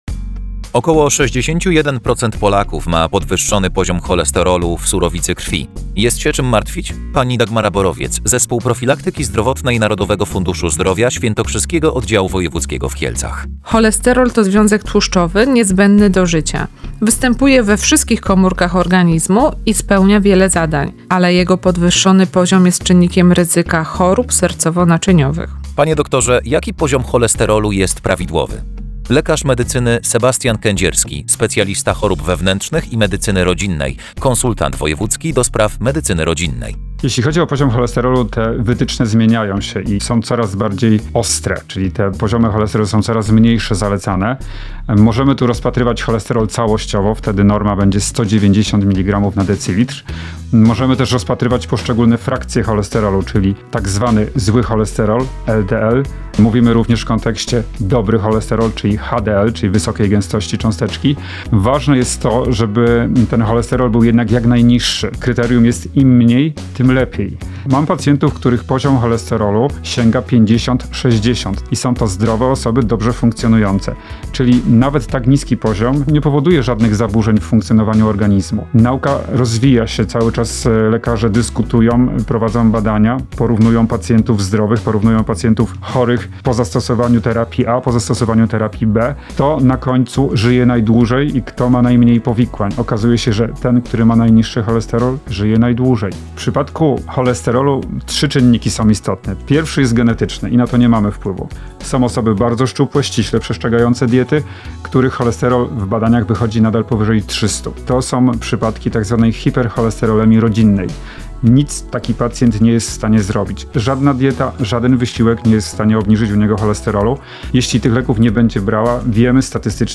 Program diety 8 tygodni do zdrowia FILM Profilaktyka cholesterolowa AUDYCJA /wp-content/uploads/2021/12/Sroda-z-Profilaktyka-Profilaktyka-cholesterolowa-15.12.2021.mp3 Źródło: Zespół Profilaktyki Zdrowotnej